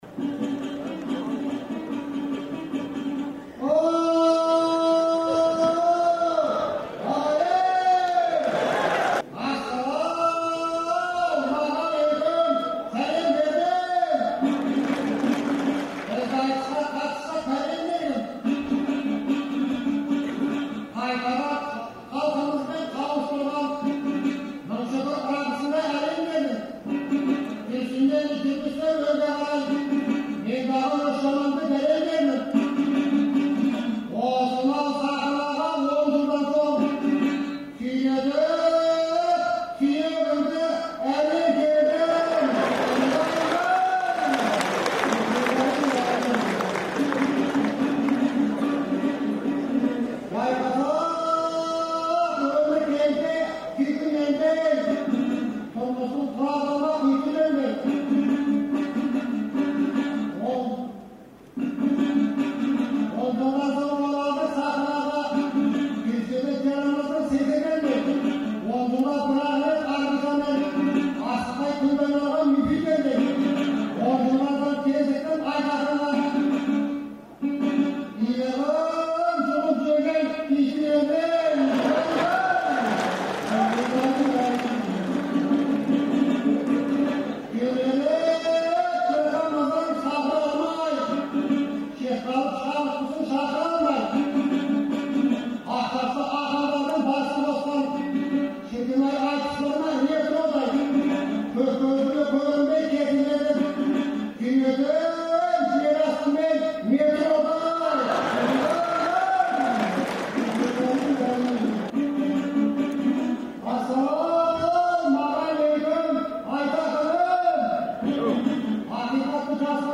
Маусымның 2-сі күні Алматыдағы Республика сарайында өткен «Ретро айтыста» Шорабек Айдаров пен Айтақын Бұлғақов сөз қағыстырды.